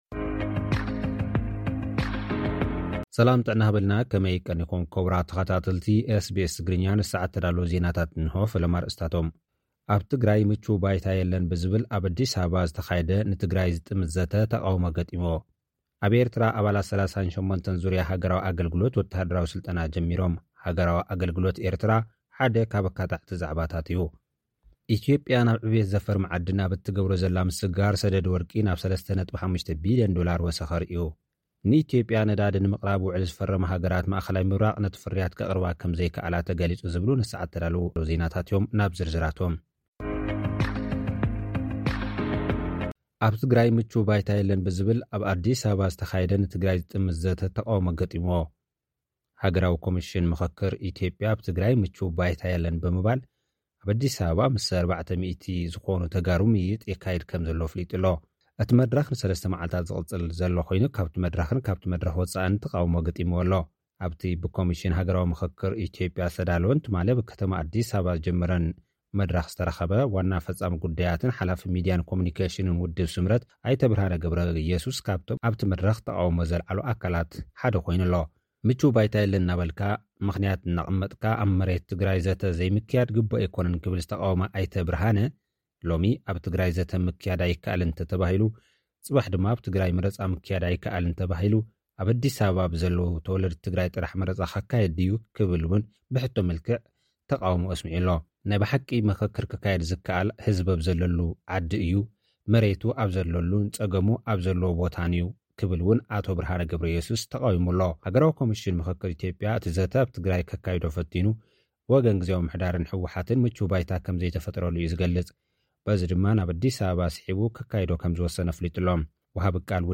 ናይ ኢትዮጵያ ሰደድ ወርቂ ናብ 3.5 ቢልዮን ዶላር ወሰኹ ኣርእዩ። (ጸብጻብ)